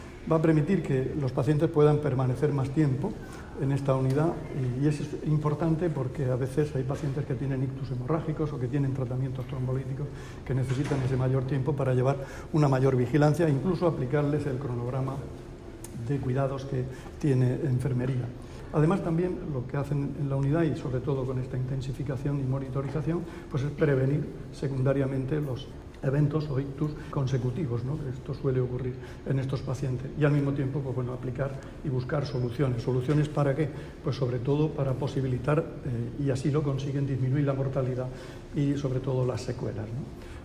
Declaraciones del consejero de Salud, Juan José Pedreño, sobre la ampliación de la unidad de ictus del hospital Virgen de la Arrixaca.
Visita a la unidad de ictus de la Arrixaca